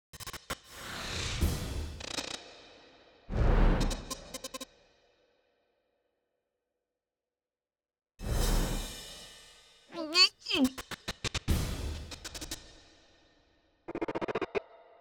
HereWeGoAgain_102_15_ST_SFX.wav